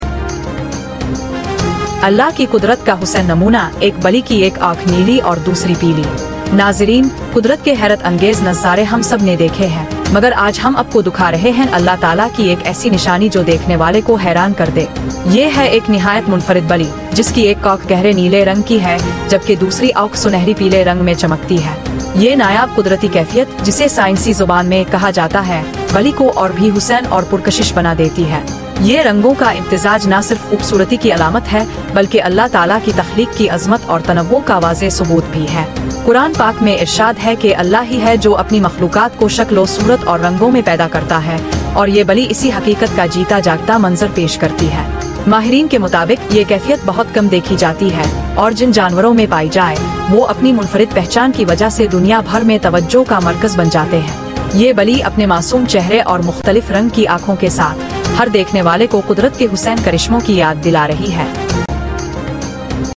Geo News headlines today